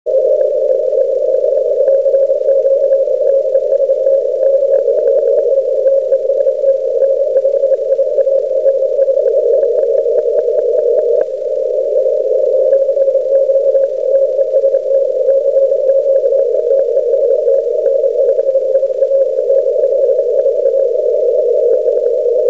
コンテスト中に録音したオーディオファイルを少し整理してみました。
またリグのLine-out端子にはサイドトーンが出てこないようで、小生が送信してる部分は無音状態になってしまってます。
途中でジャミングが大きくなっているところは、DPと切り替えてみたところです。